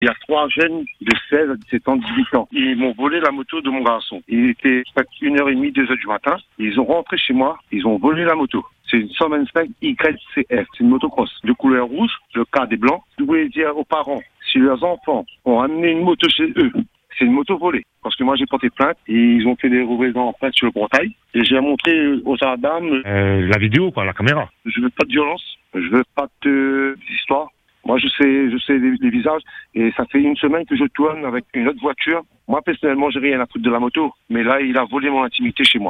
Dans son témoignage, que vous allez entendre, ce père évoque le sentiment d’insécurité qui s’installe après un tel acte.